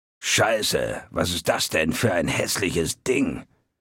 Fallout 3: Audiodialoge
Malegenericghoul_dialoguemsmini_hello_000c9cca.ogg